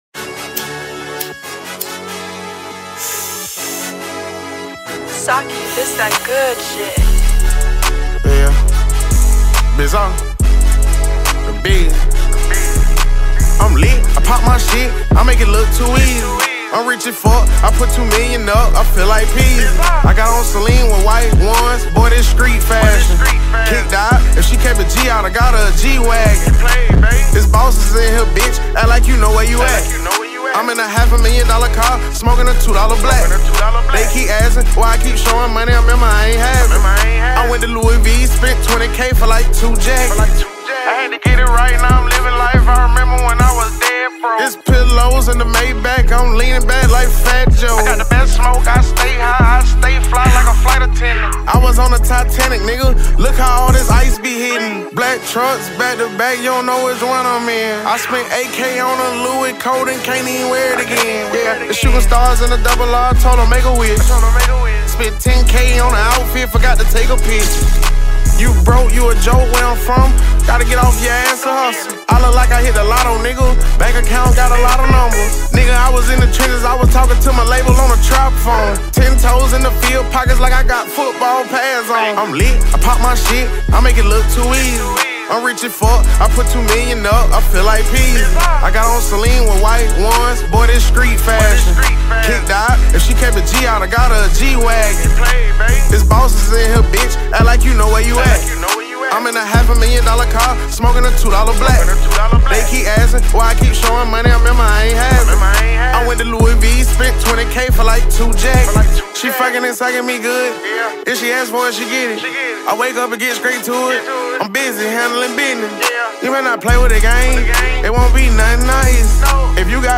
smooth delivery